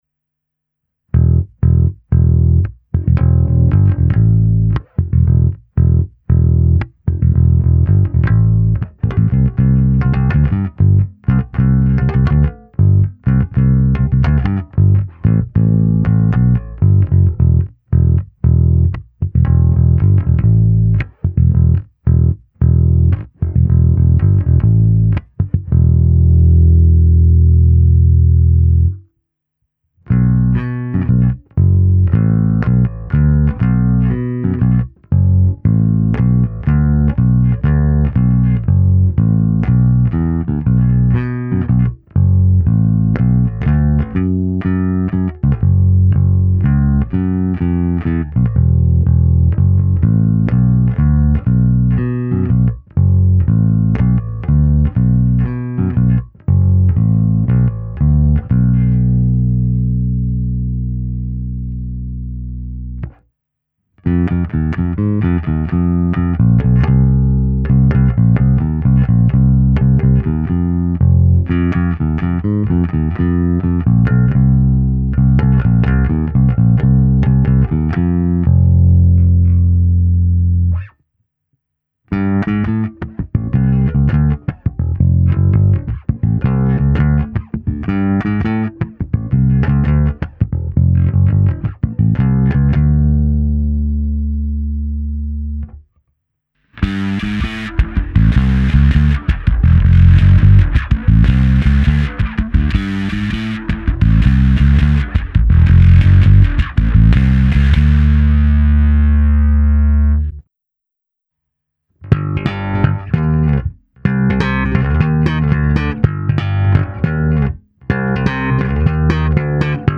Ukázka se simulací aparátu
Parádní tučný, pevný, zvonivý zvuk s těmi správnými středy, které tmelí kapelní zvuk a zároveň dávají base vyniknout.